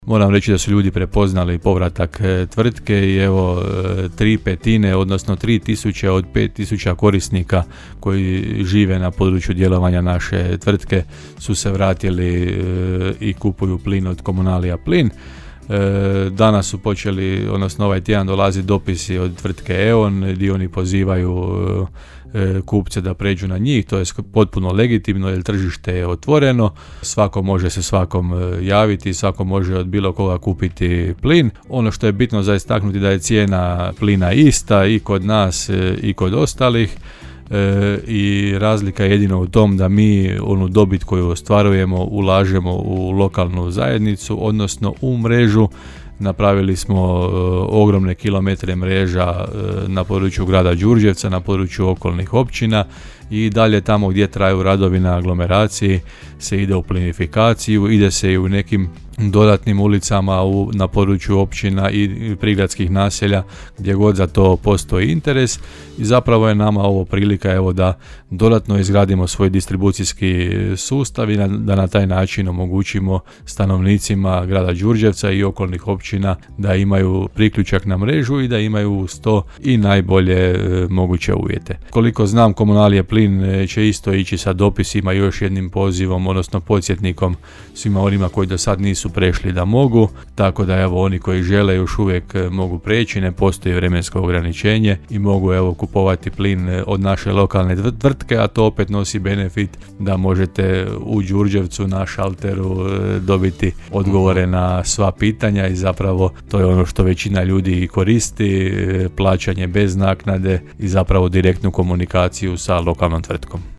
U ovotjednoj emisiji Gradske teme Podravskog radija gostovao je gradonačelnik Hrvoje Janči, koji se osvrnuo na aktualnu situaciju s opskrbom plinom u Đurđevcu.